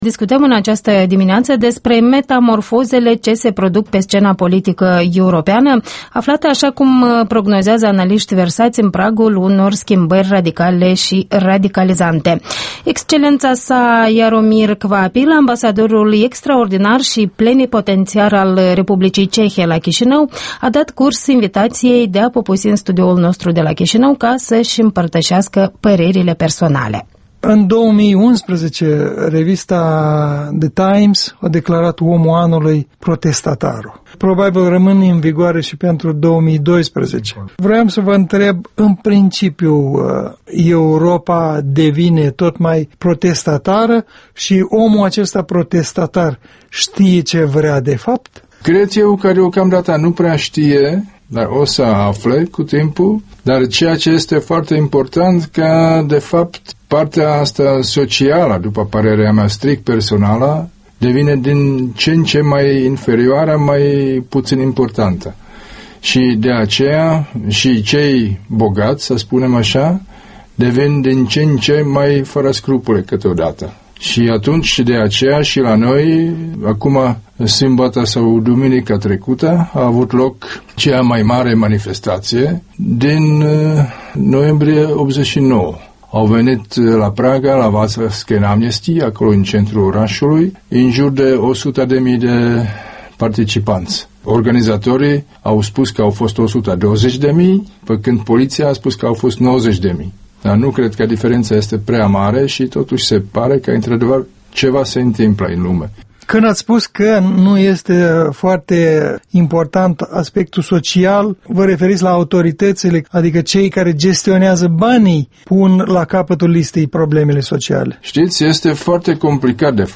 Interviul dimineții la EL: cu Jaromir Kvapil, ambasadorul Cehiei la Chișinău